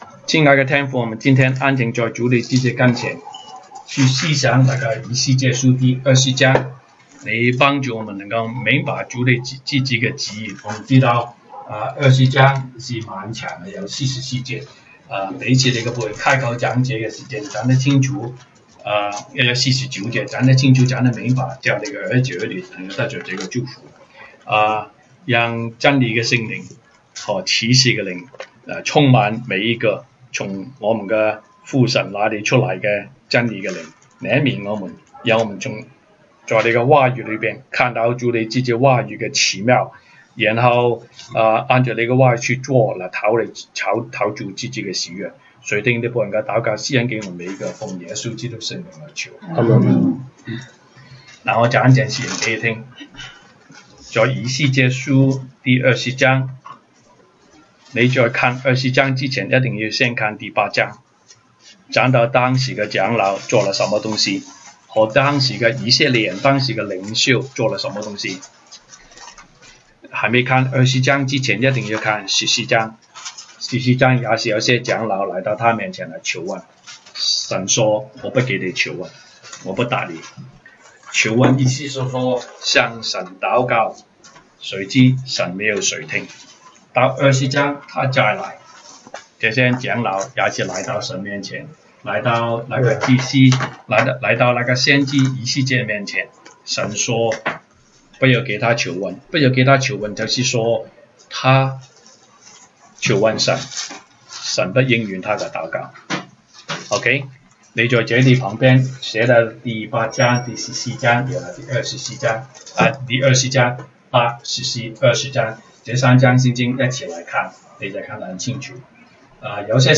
週一國語研經 Monday Bible Study « 週一國語研經 以西結書 Ezekiel 28:1-26 東北堂證道